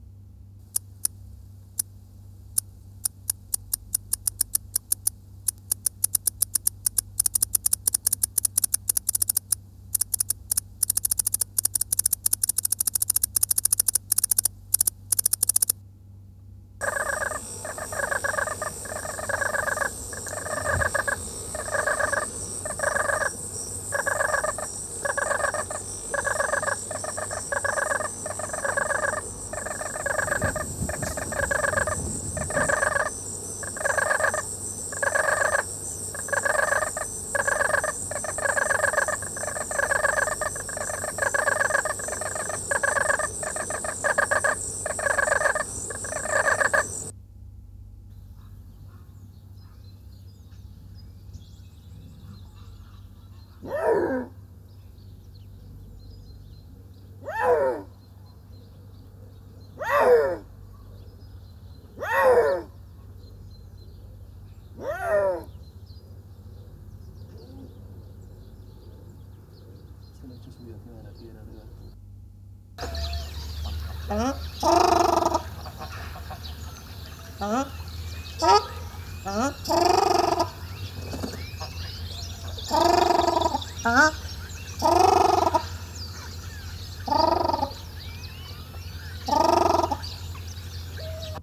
Marantz PMD 660 Oade Modificado
Los sonidos grabados fueron emitidos por un amplicador Aiwa AFB-7 el mismo altavoz amplificado en una habitación semiinsonorizada.
Las grabaciones han sido sincronizadas con dos micrófonos Sennheiser MKH 416 ? P 48 U 3.
Un ortóptero (grabado en la Isla de El Hierro, Islas Canarias)
Un anuro (grabado en la Isla de Bali, Indonesia)
Un mamífero (grabado en cautividad)
Un ave (ave acuática).